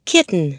KITTEN.mp3